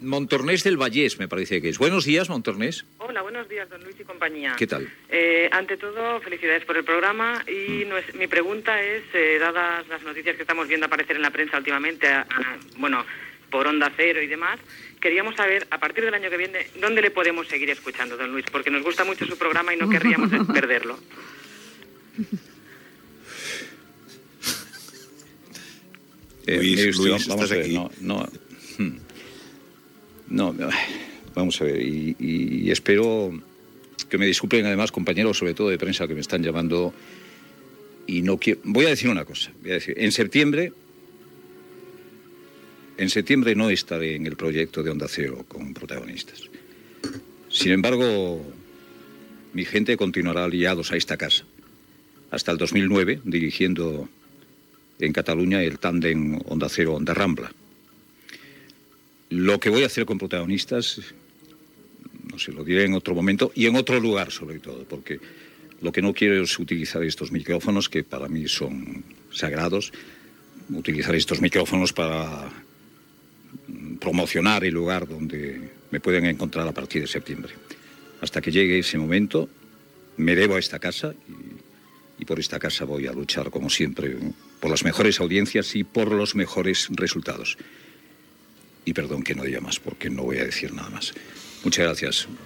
Una oïdora pregunta a Luis del Olmo on estarà la següent temporada radiofònica. Diu que no seguirà a Onda Cero el mes de setembre.
Info-entreteniment
FM